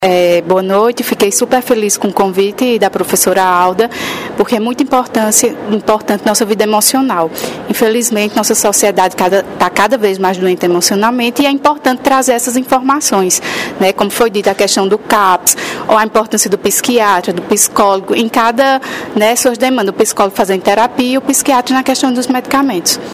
Abaixo as entrevistas completas: